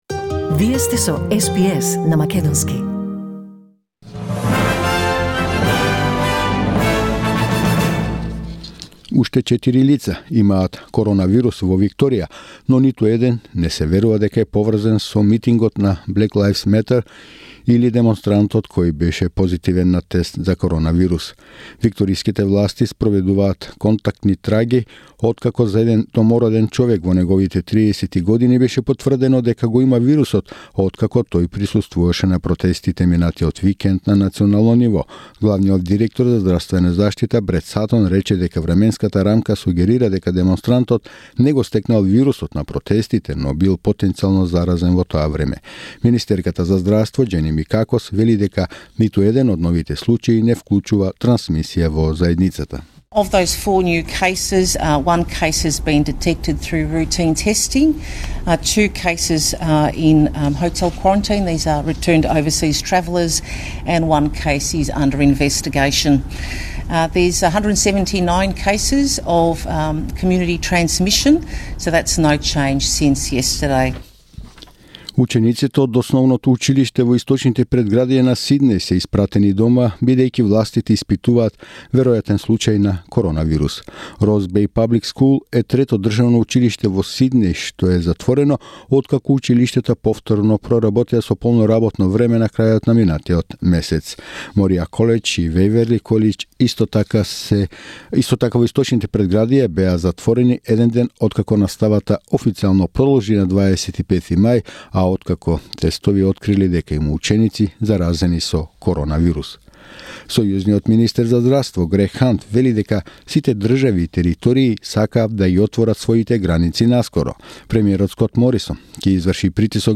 SBS News in Macedonian 12 June 2020